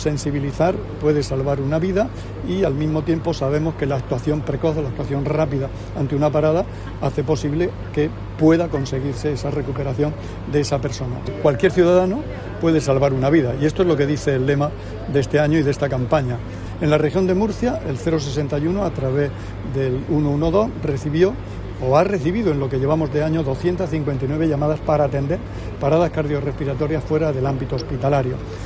Declaraciones del consejero de Salud, Juan José Pedreño, sobre la importancia de conocer las maniobras de RCP.[mp3]